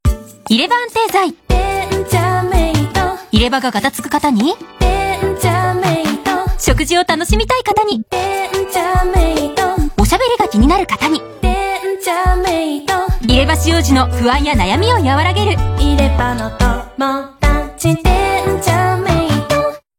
入れ歯安定剤・デンチャーメイトのCMはやけに軽快な曲が流れています。